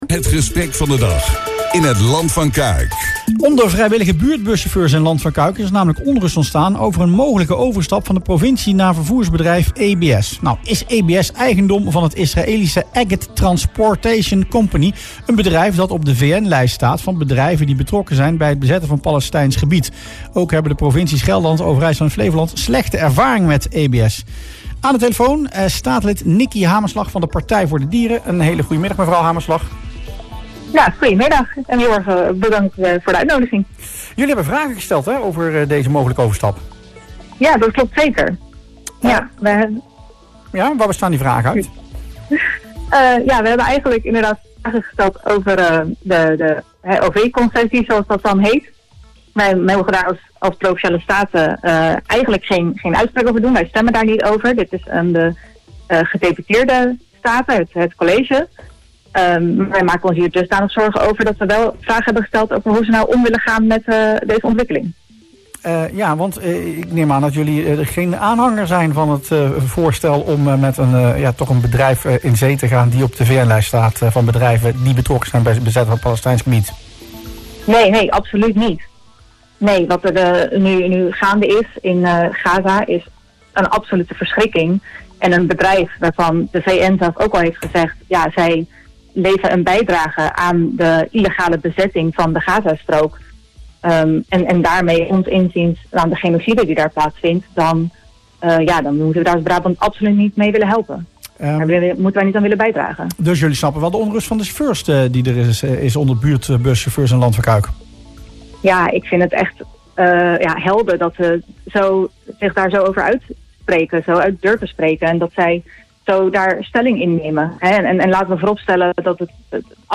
PvdD-statenlid Nikky Hamerslag in Rustplaats Lokkant